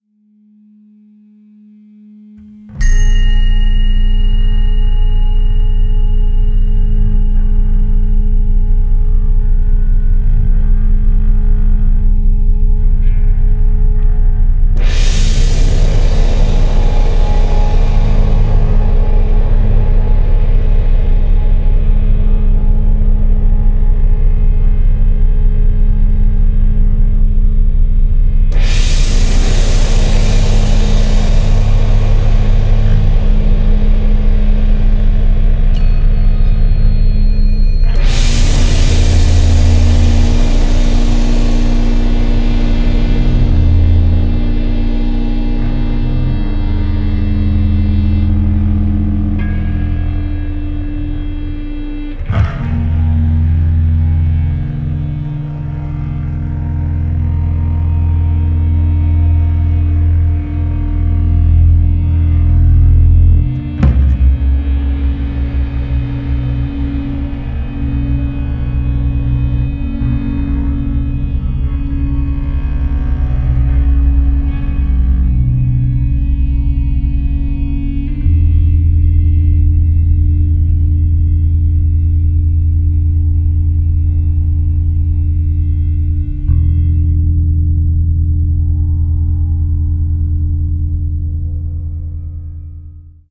electrified baritone saxophone
electric guitar
analog synthesizer
electric bass
drums, acoustic and electronic percussion sets
Recorded in Moulins-sur-Ouanne, France, summer 2019.